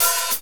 Index of /90_sSampleCDs/Northstar - Drumscapes Roland/DRM_Medium Rock/HAT_M_R Hats x